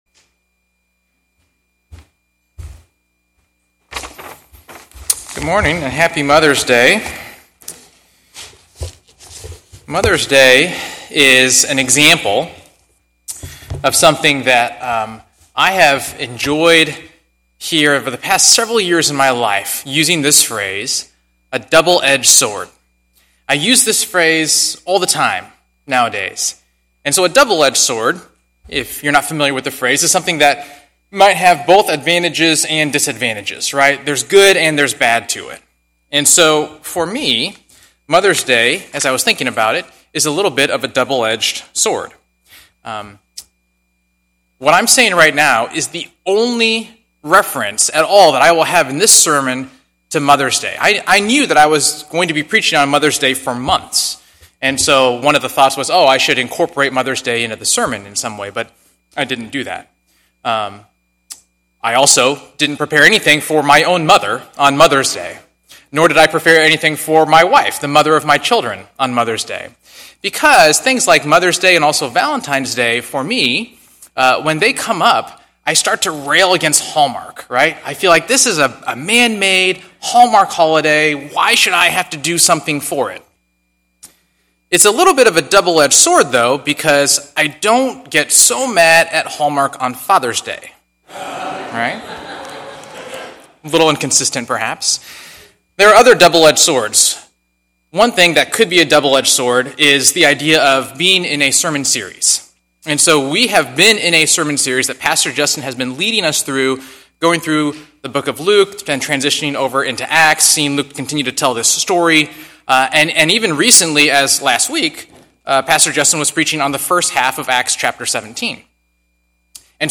Sermons by CCCI